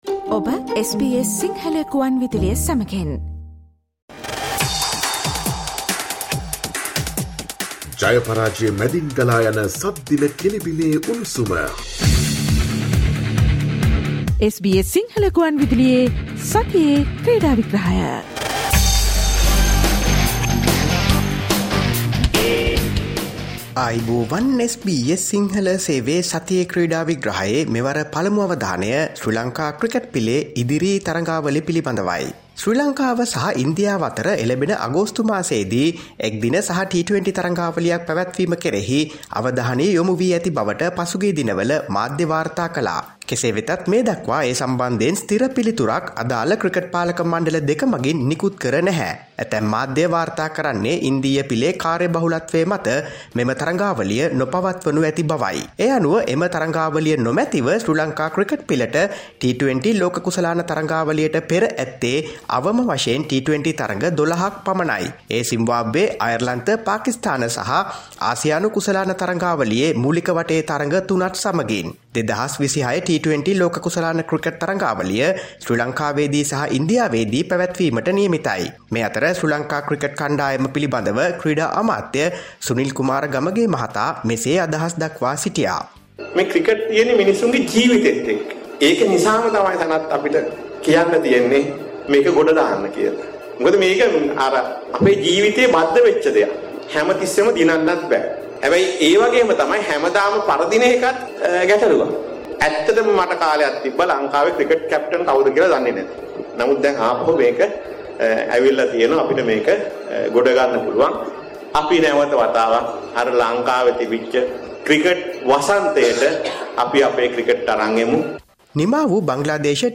'මම ලංකාවේ ක්‍රිකට් නායකයා කවුද කියලවත් දන්නැති කාලයක් තිබුනා', ක්‍රීඩා ඇමති කියයි: ක්‍රීඩා විග්‍රහය